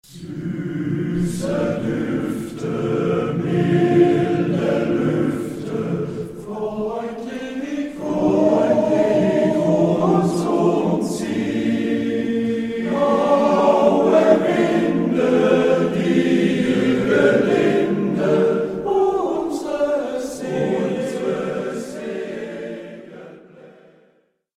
Naturlieder